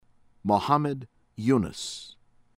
SIRCAR, JAMIRUDDIN jah-meer-oo-DIHN   SHAHR-kahr